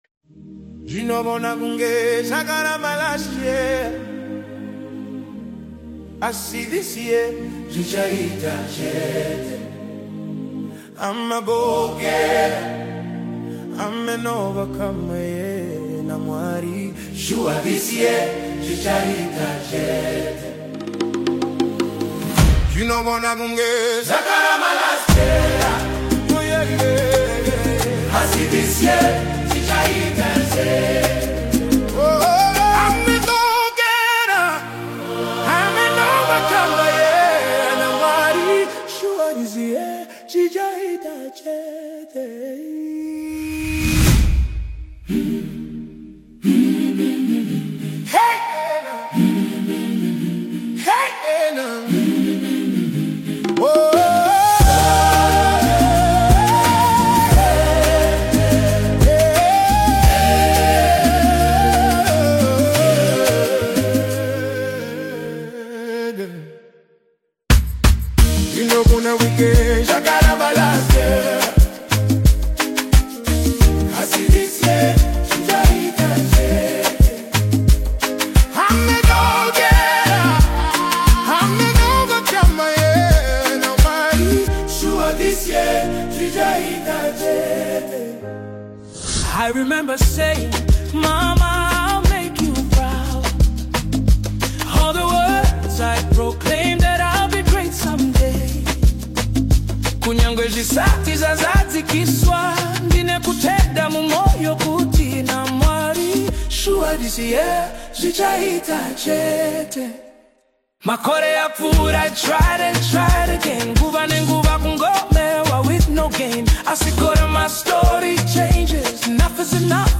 uplifting gospel single